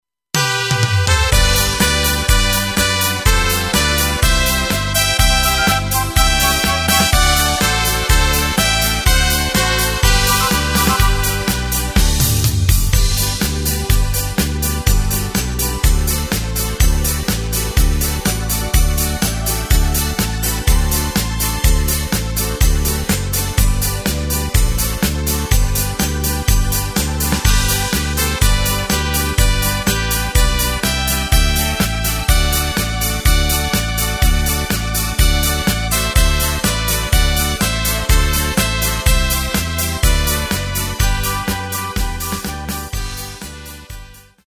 Takt:          2/4
Tempo:         124.00
Tonart:            G
Schlager-Polka aus dem Jahr 1996!
Playback mp3 Mit Drums